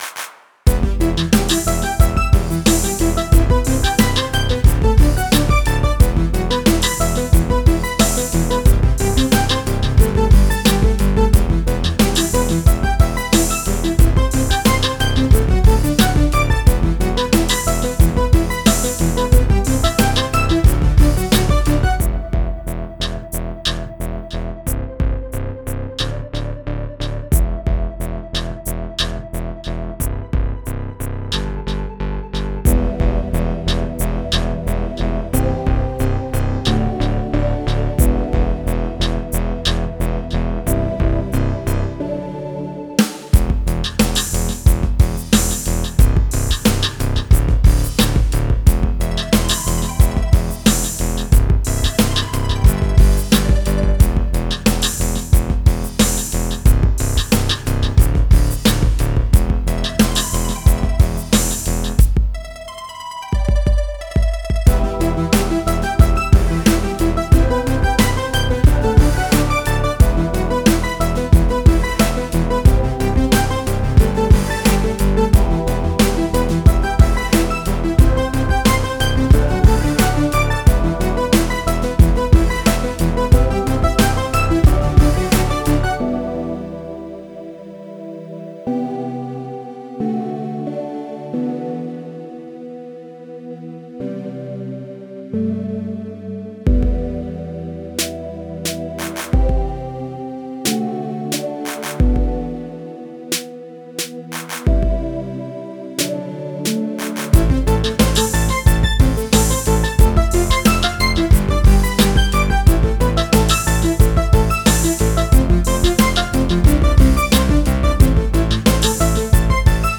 Currently browsing: Cinematic